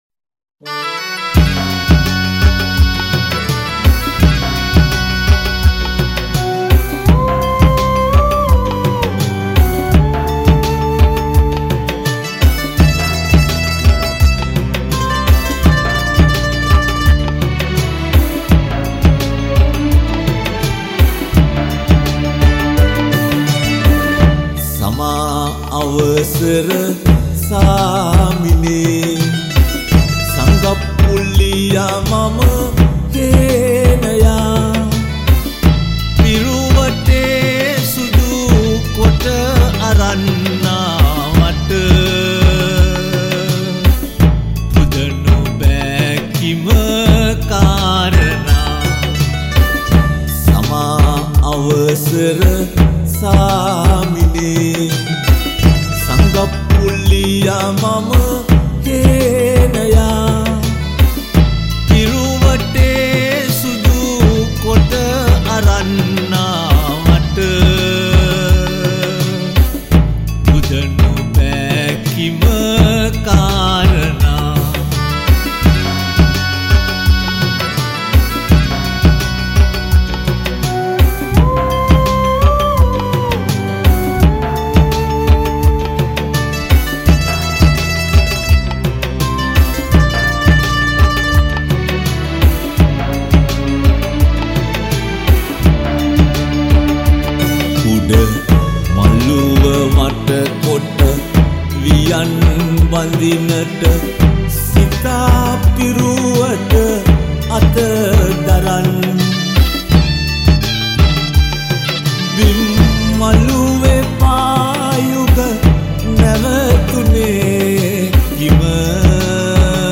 All these songs were recorded (or remastered) in Australia.
Vocals